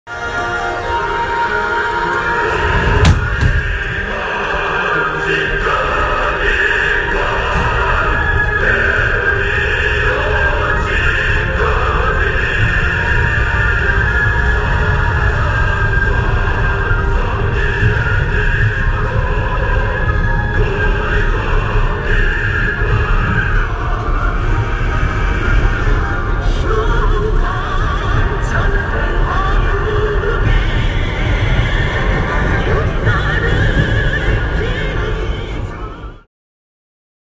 Pyongyang sound